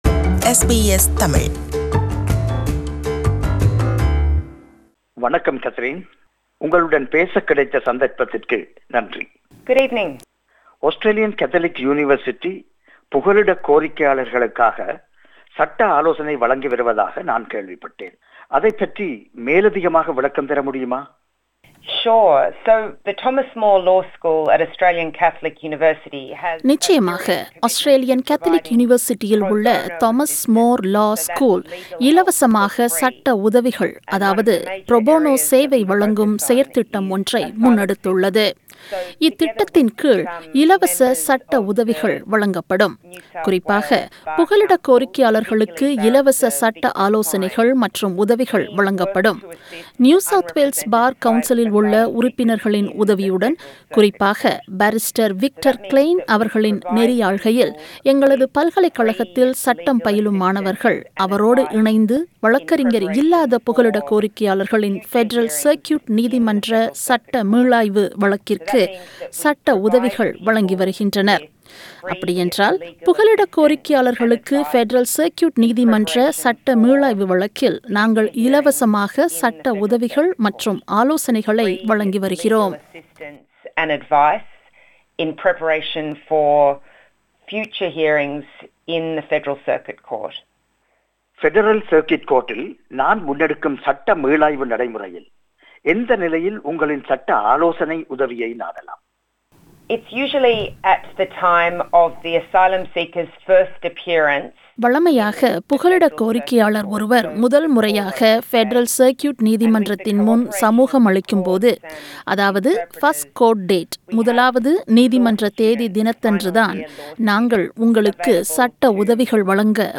Australian Catholic Universityல் புகலிடக் கோரிக்கையாளர்களுக்காக இலவச சட்ட உதவிகள் வழங்கும் செயற்திட்டம் ஒன்று Federal Circuit நீதிமன்ற தலைமை நீதிபதியின் அனுமதியுடன் ஆரம்பிக்கப்பட்டுள்ளது. இது குறித்து புகலிடக் கோரிக்கையாளர் ஒருவர் கேட்கும் கேள்விகளுக்கு பதில் கூறுகிறார்